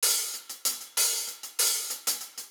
History HiHat Loop.wav